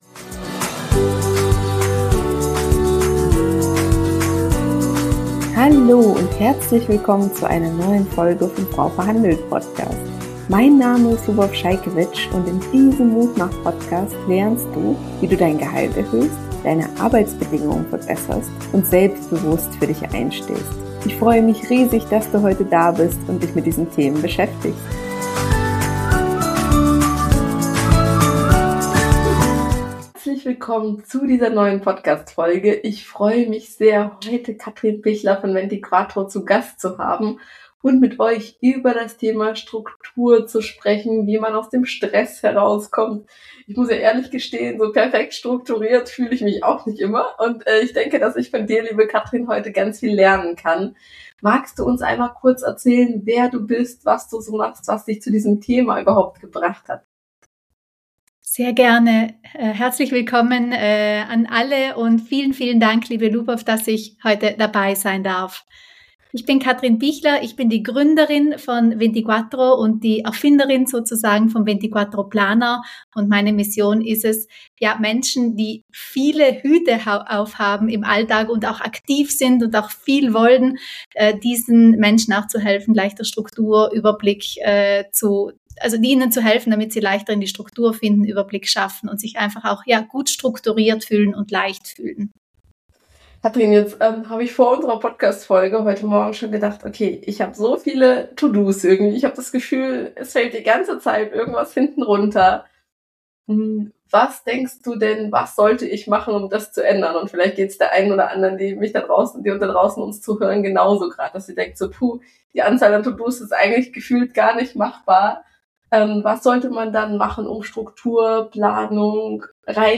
#115 Von gestresst zu strukturiert - Interview